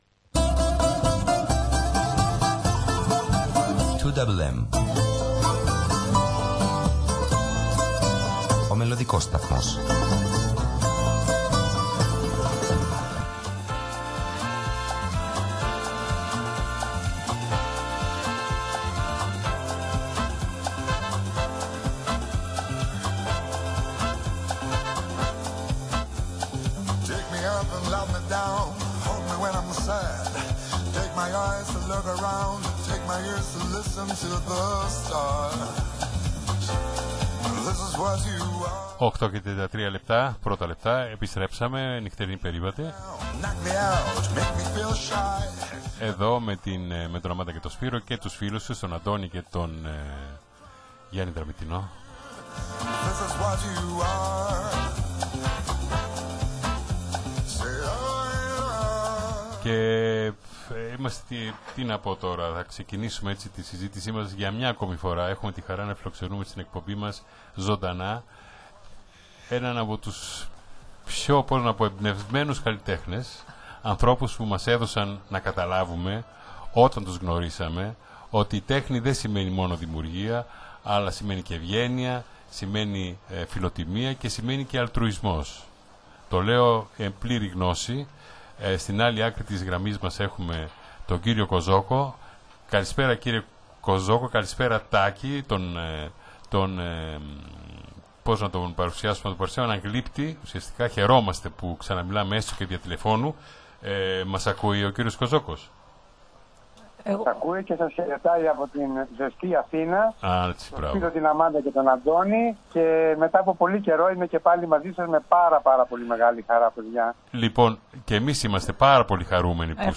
ζωντανή συνέντευξη